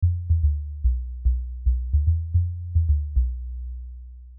Das ist für mich ein Lowpass mit hohem Q. Je niedriger die Flankensteilheit, desto breiter wird es: Hier was ich gemacht habe: Der Rest ist Mixing(Processing) und Finetuning im Track selbst (Hüllkurven anpassen).